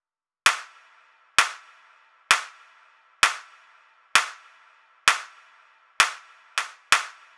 Claps.wav